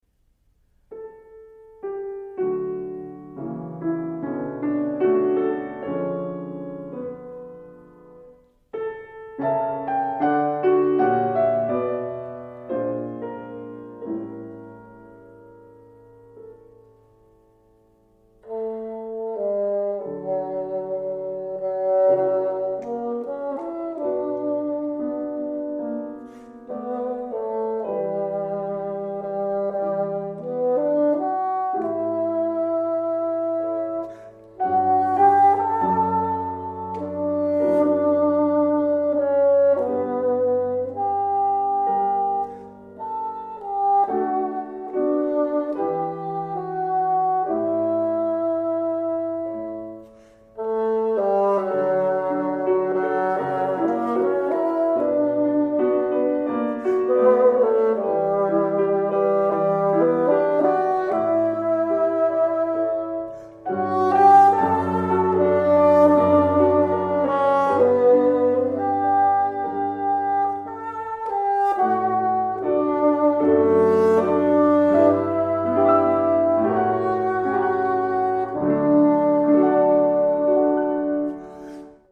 癒しの音楽